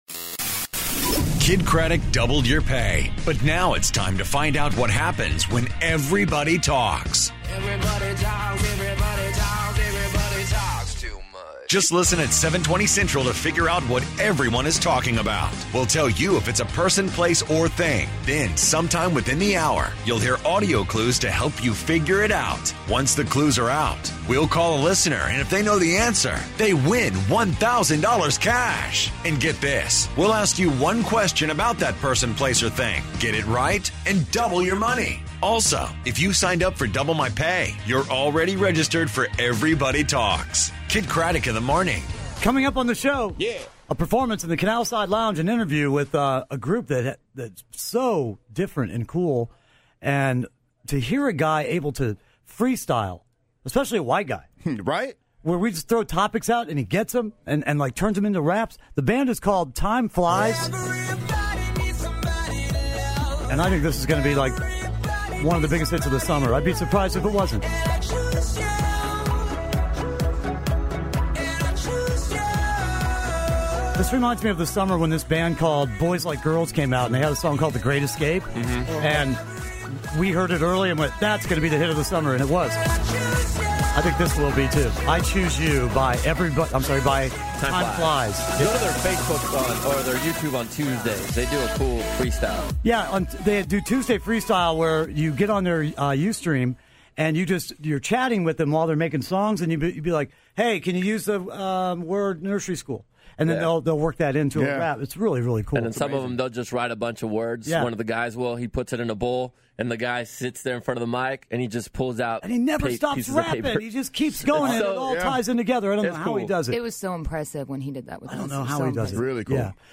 and Timeflies in studio